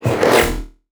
MAGIC_SPELL_Teleport_mono.wav